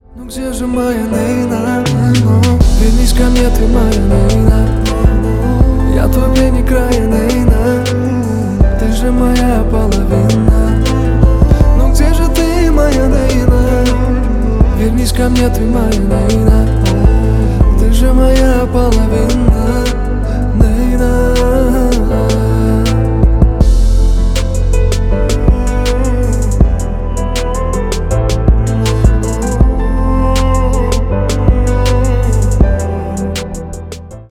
• Качество: 320, Stereo
мужской вокал
мелодичные
спокойные
пианино
лирические
баллада